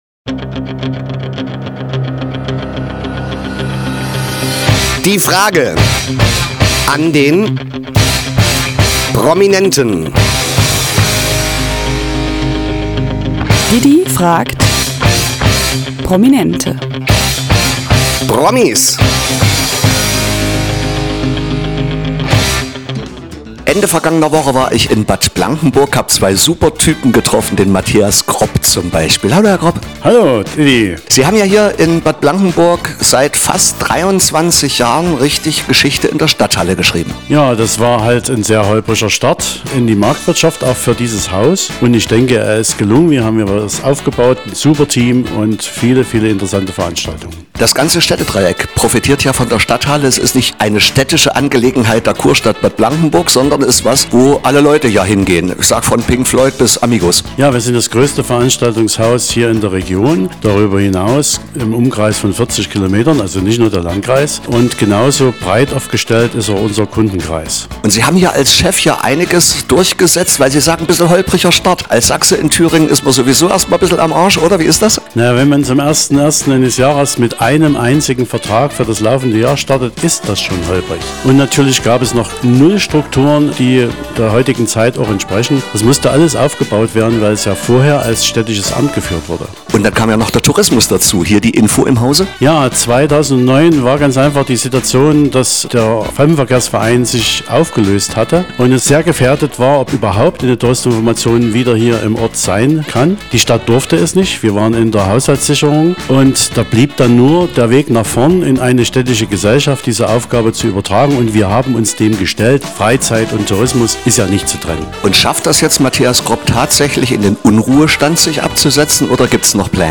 SRB-Interview